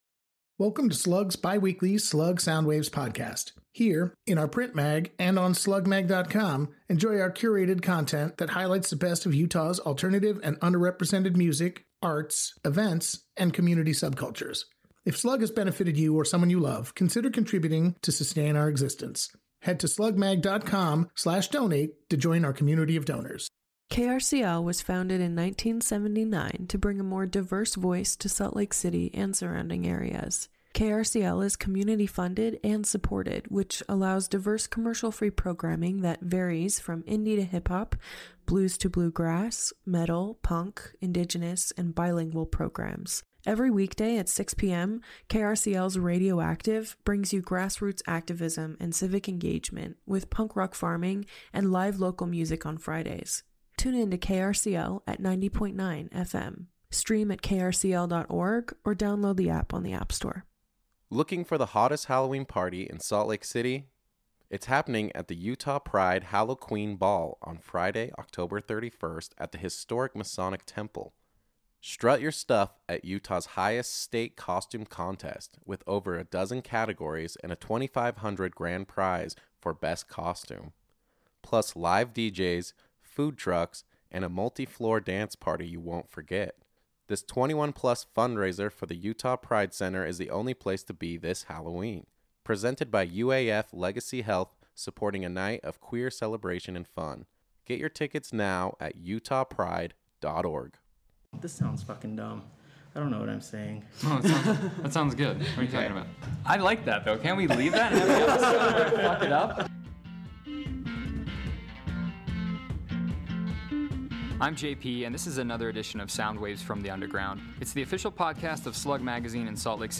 Halloween Throwback: Heavy Metal Roundtable